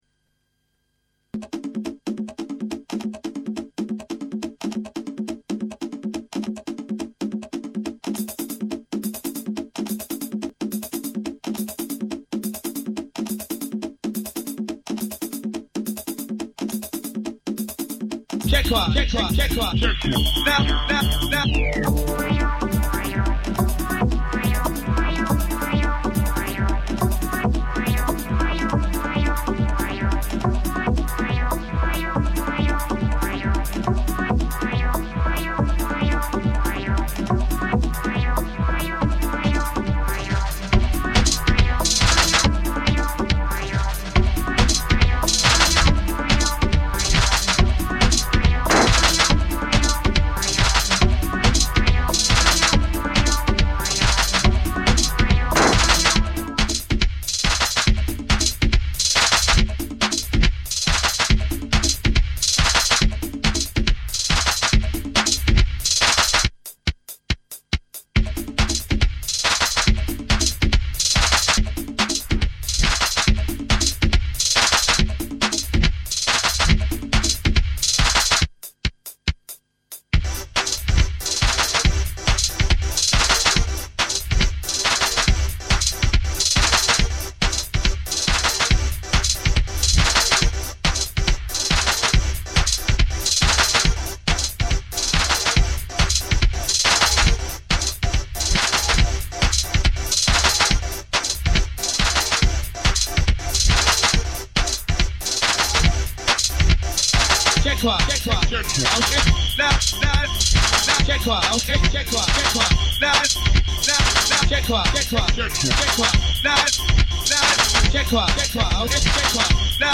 dance/electronic
house type tune featuring a sample from an old rave